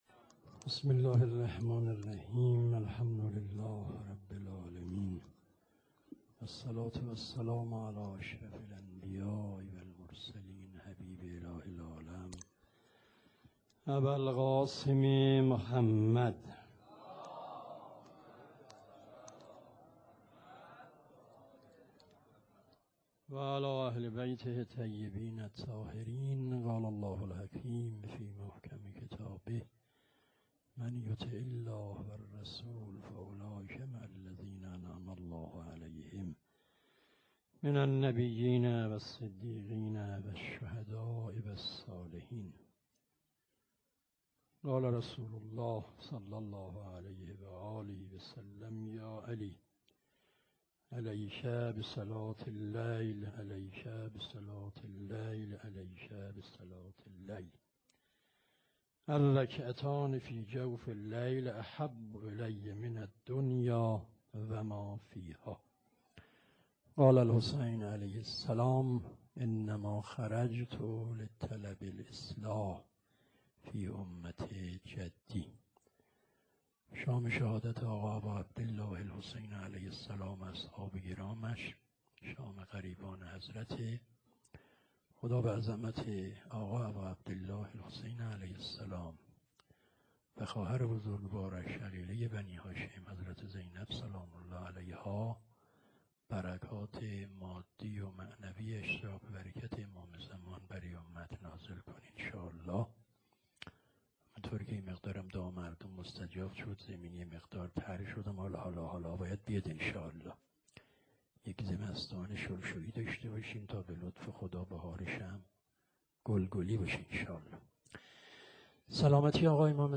سخنرانی حاج آقا فلسفی _ شب یازدهم.wma